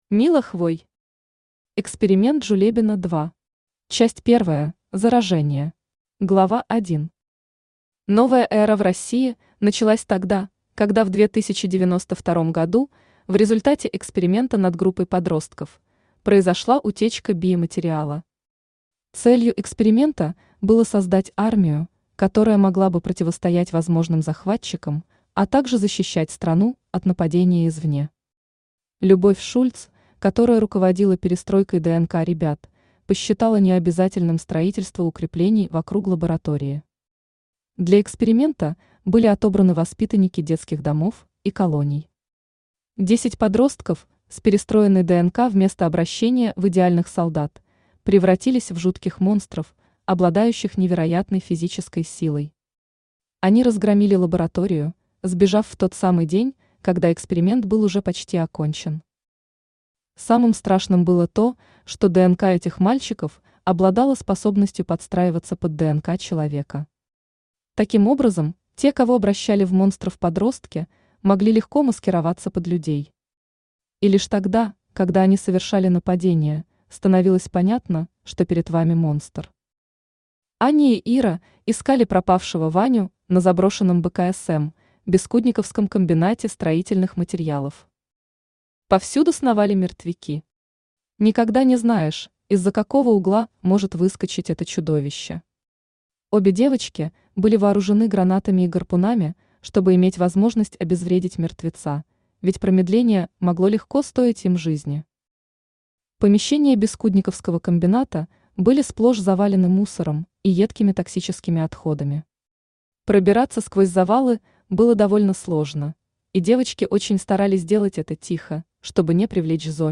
Аудиокнига Эксперимент Жулебино 2. Часть первая: Заражение | Библиотека аудиокниг
Часть первая: Заражение Автор Мила Хвой Читает аудиокнигу Авточтец ЛитРес.